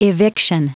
Prononciation du mot eviction en anglais (fichier audio)
Prononciation du mot : eviction